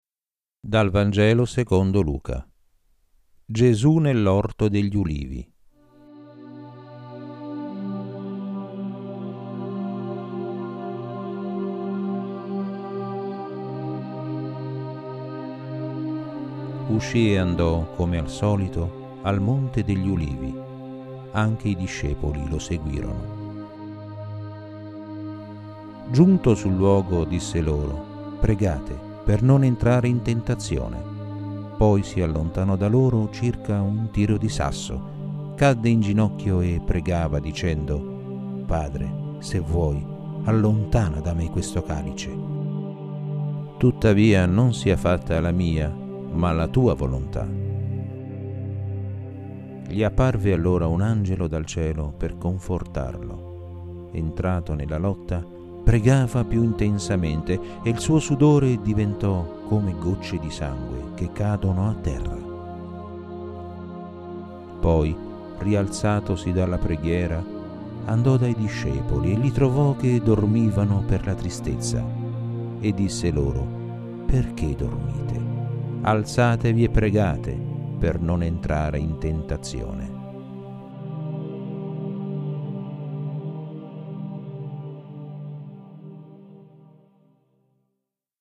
I promessi sposi - audiolettura realizzata per l'editore Bulgarini